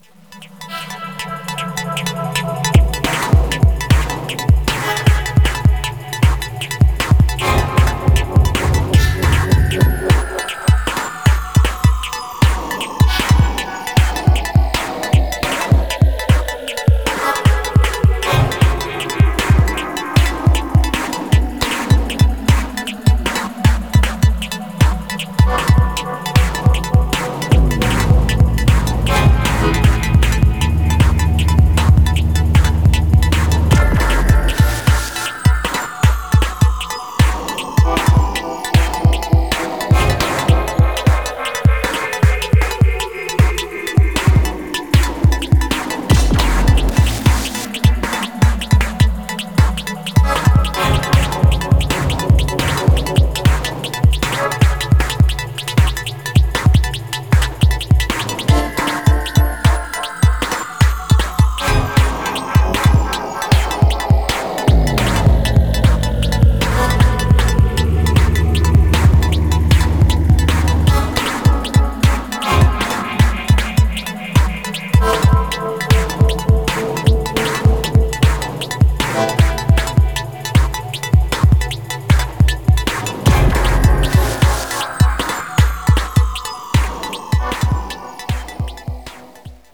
ハーフタイム、ポリリズムが錯綜、自動生成されてゆくような、テクノの何か得体の知れない領域が拡大しています。